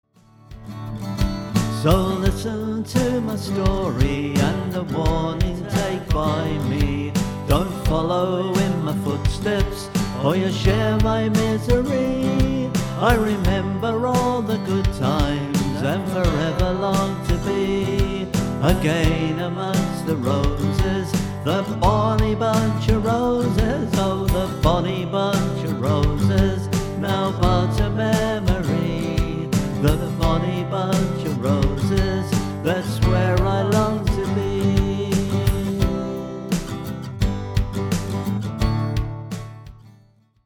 This is a folk song, so don't expect a happy ending.
demo mix